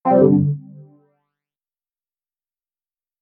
Unequip.ogg